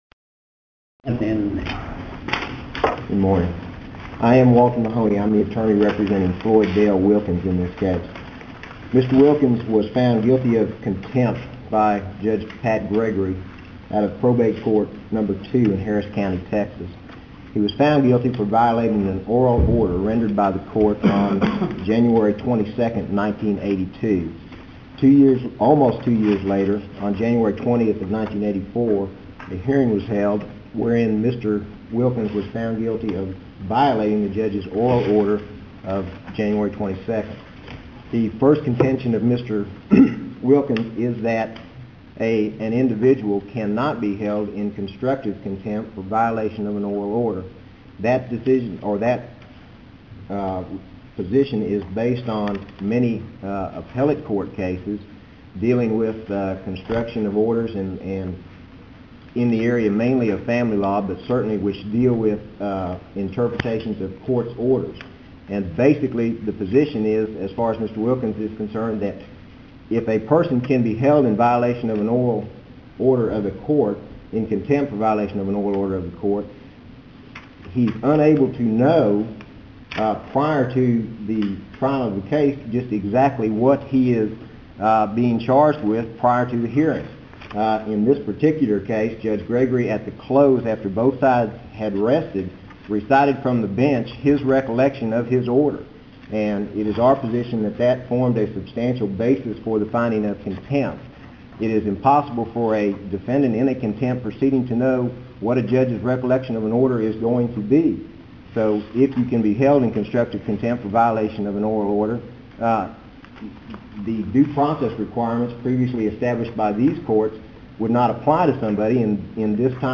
Case No. C-2737 Oral Arguments Audio (MP3)